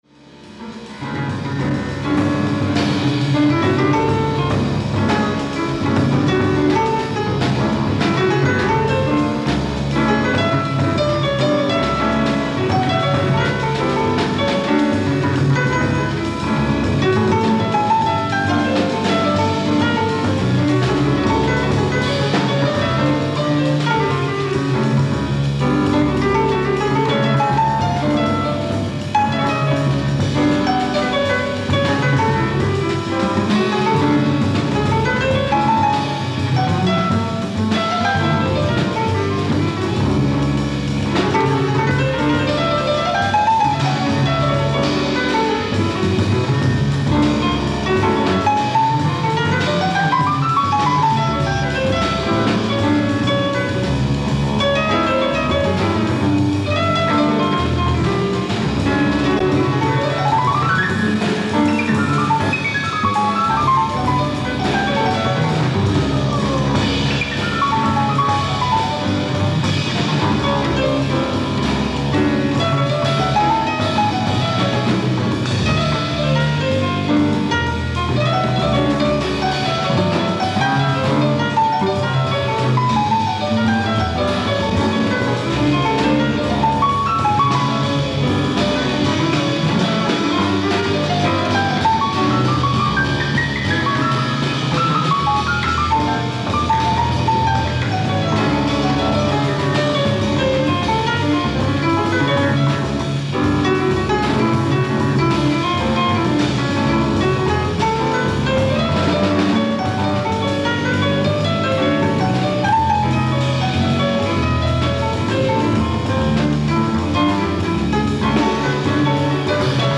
ライブ・アット・ミネアポリス、ミネソタ 11/14/1977
※試聴用に実際より音質を落としています。